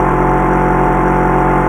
KART_Engine_loop_2.ogg